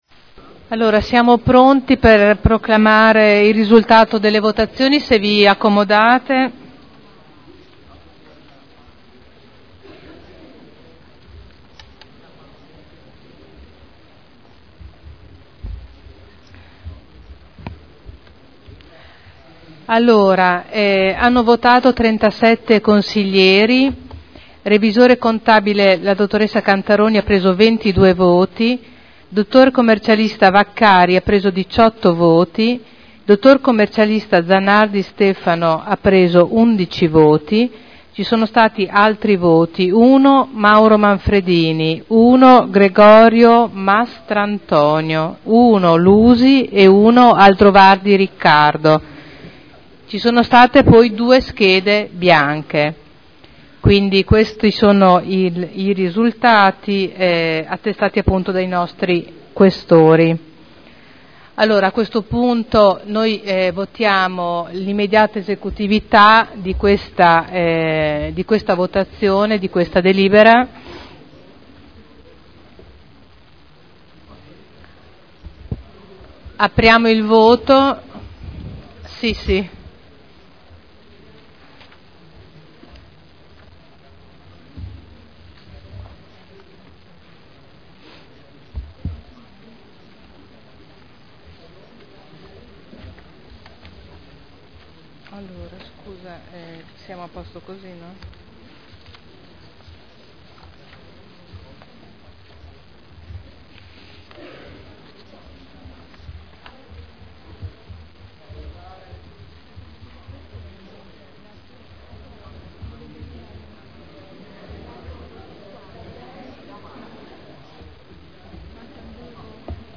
Presidente — Sito Audio Consiglio Comunale
Il Presidente mette ai voti la Delibera Rielezione del Collegio dei Revisori dei conti per il triennio 2012 - 2014 (Conferenza Capigruppo del 23, 30 gennaio e 5 marzo 2012).